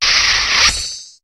Cri de Scalproie dans Pokémon HOME.